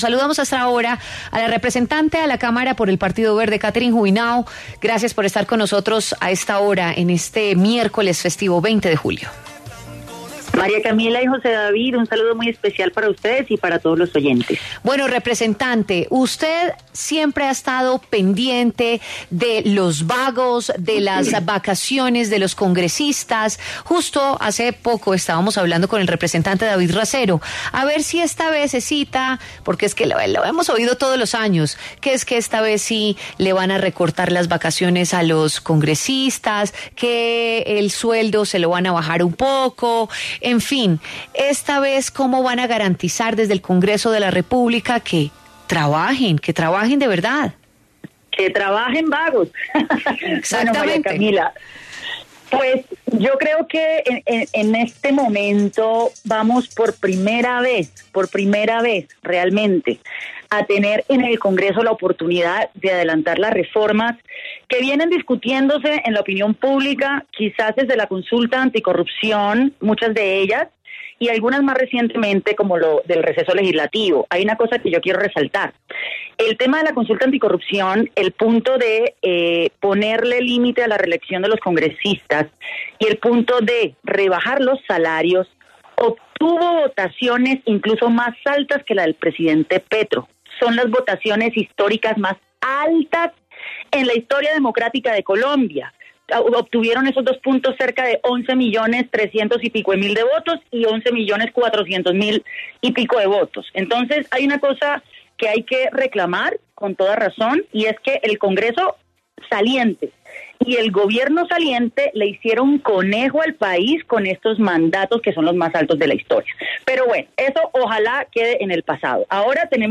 Catherine Juvinao, representante del Partido Verde, habló en La W sobre los principales retos que tendrá el nuevo Congreso de la República. Dio luces de algunos de los proyectos de ley que presentará.